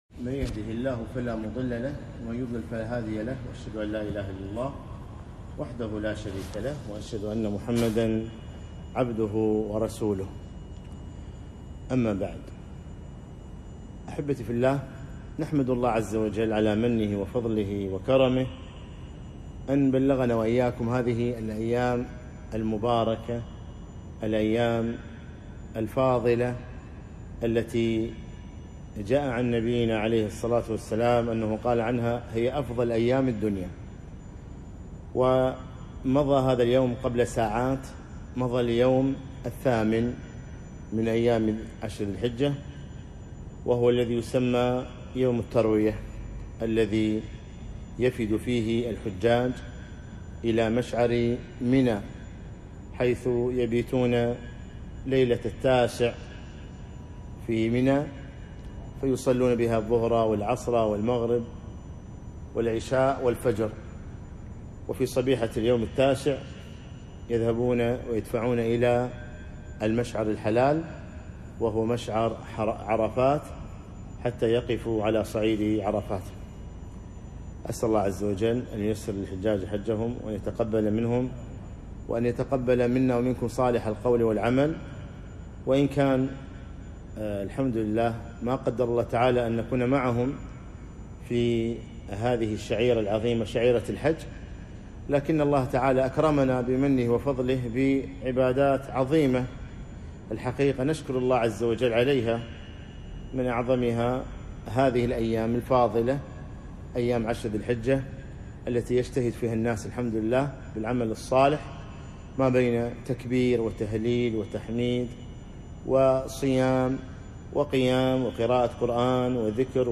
محاضرة - الأضحية أحكام وآداب